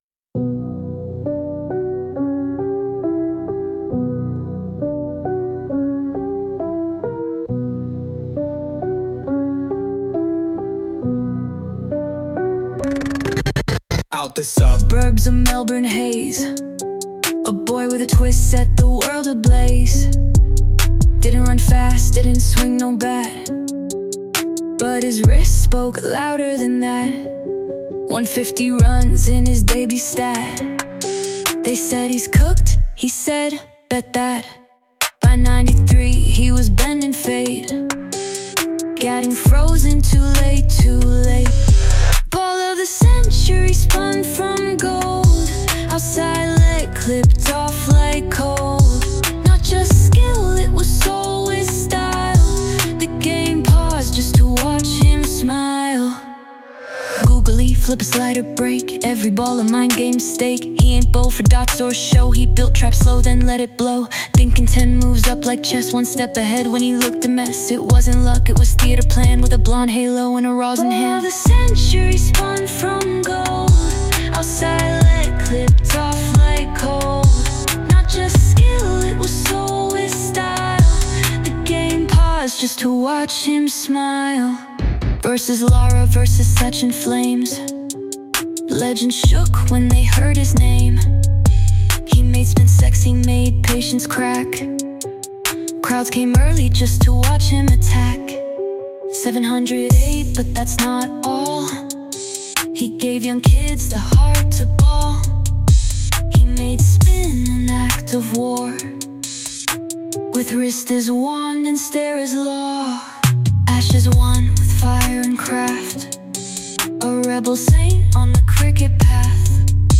original rap tribute